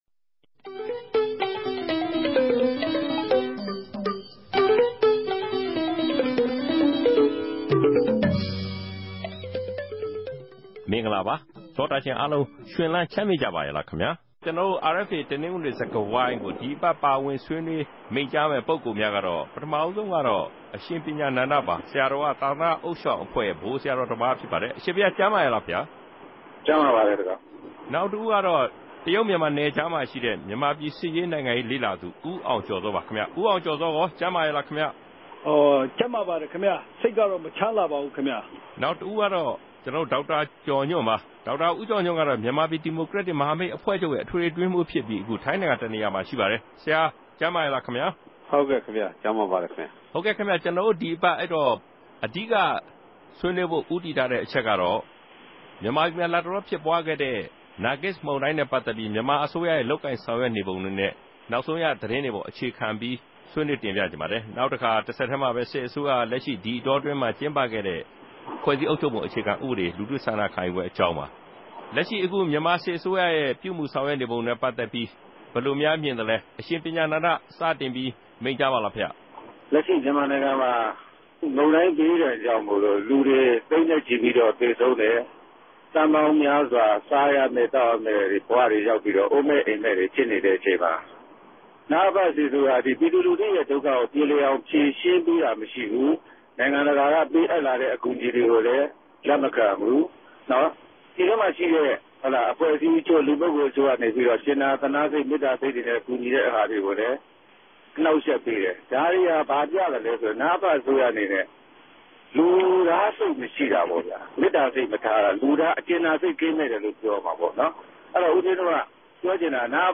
တနဂဿေိံြ ဆြေးေိံြးပြဲ စကားဝိုင်း။